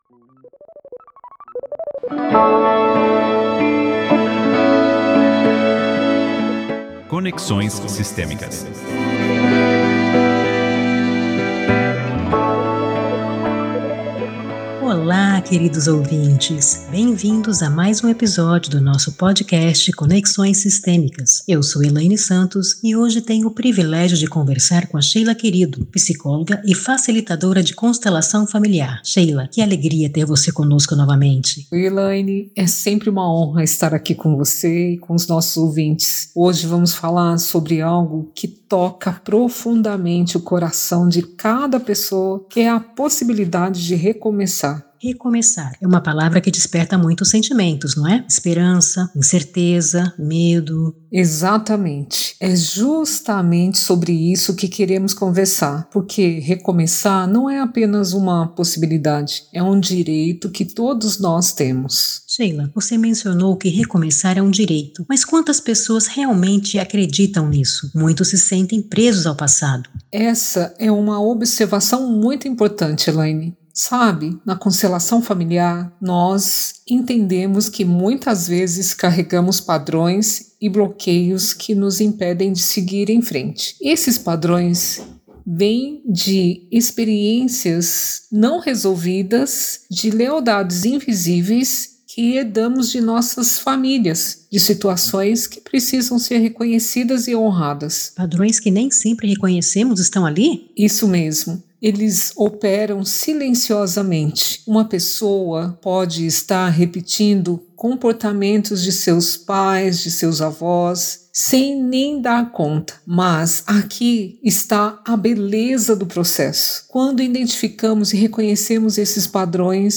💬 Uma conversa que vai te inspirar a dar o primeiro passo em direção à vida que você realmente merece.